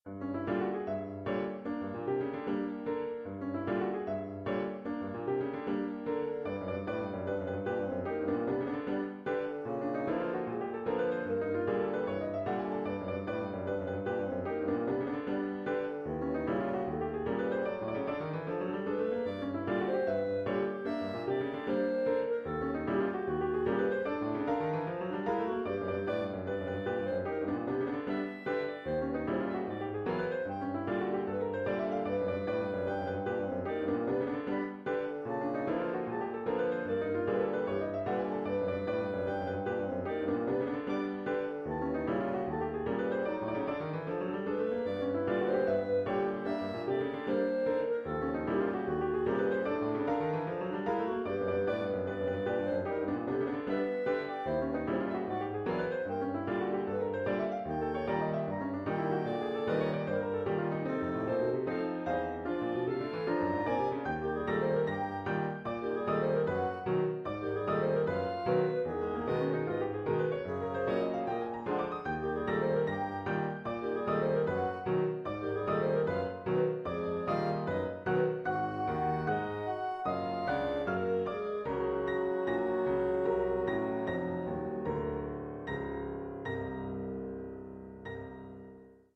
pour piano, basson et clarinette en si bémol
La mélodie principale est donnée au début par le basson, relayé par la clarinette. La suite du morceau développe ce thème. L’écriture est dans l’ensemble assez dissonante, dans l’esprit d’une fantaisie polytonale.